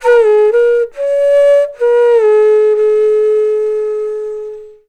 FLUTE-A07 -R.wav